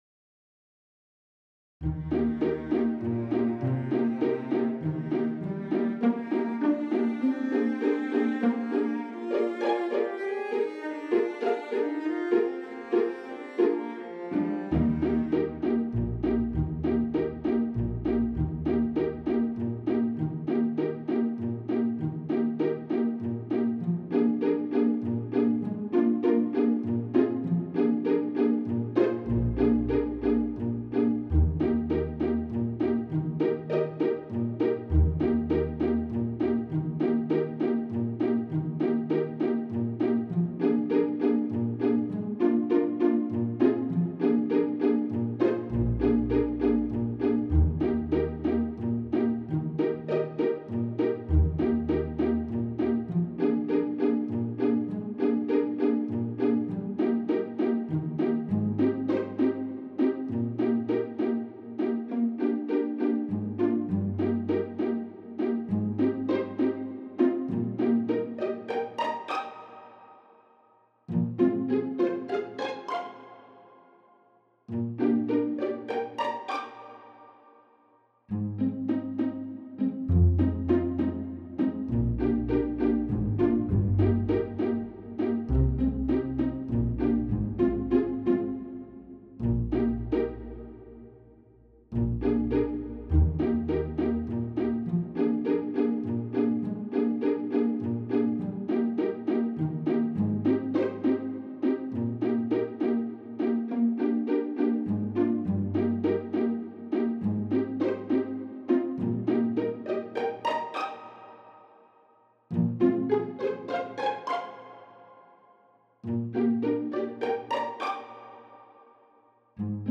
Alto Saxophone
Allegretto =116 Allegretto =120
3/4 (View more 3/4 Music)
Classical (View more Classical Saxophone Music)